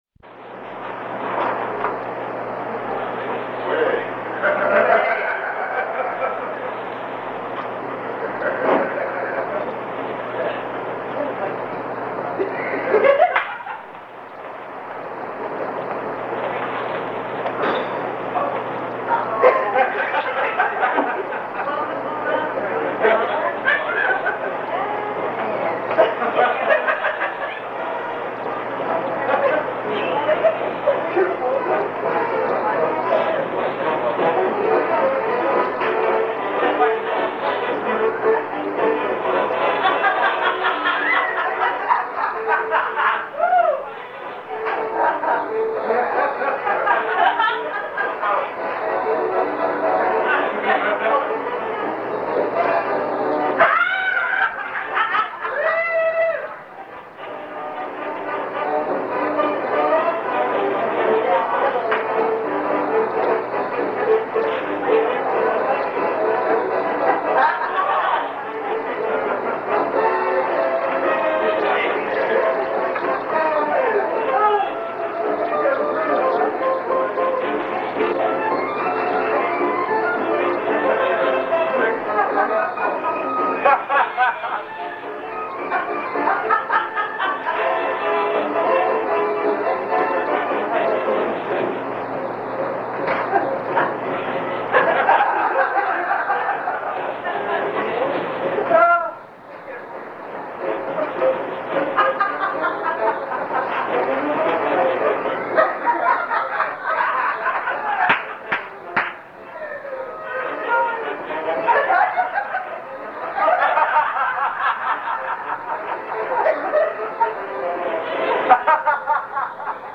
This one night in 1992 they had a stand up contest at this little club in the Valley.
a bunch of laughing, and me being acknowledged at the end.
It started out with a chuckle here and there, and then it built up to a pretty good night.
voice recorders to tape the set, and would just hit the play button at my table when my name was called.
mp3 of my silent stand up set from that night, and the video from after the show that got me the win: